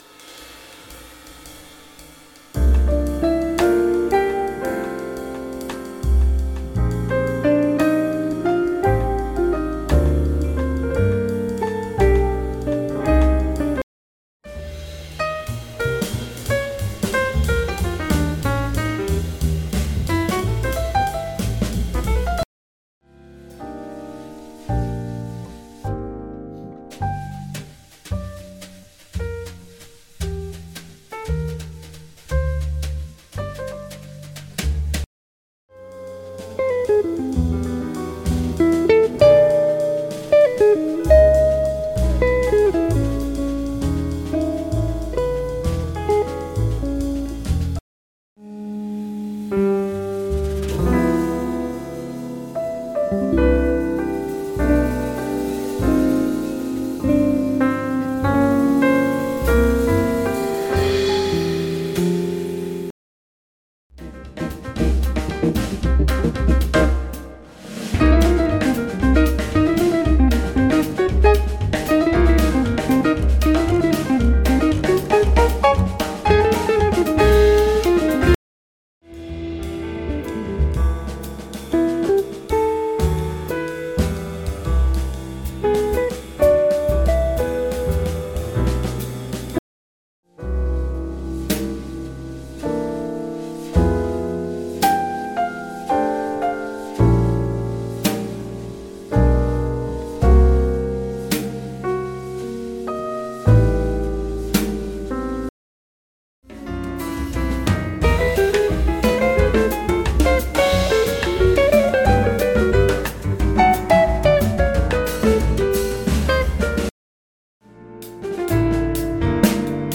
Een album vol inspiratie en passie.
Hoe kan het ook anders; het is jaaaaazzzzzzzzzz!
gitaar
drums.